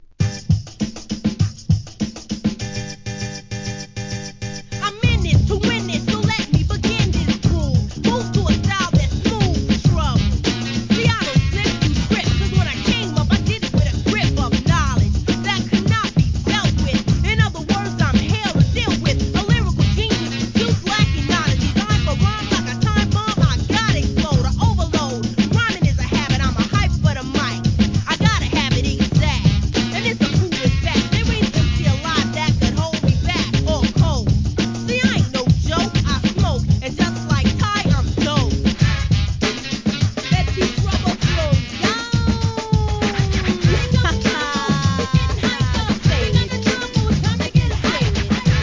HIP HOP/R&B
1990年、フィメールRAP!!